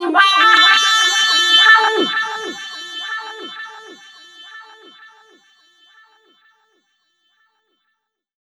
VOCODE FX1-R.wav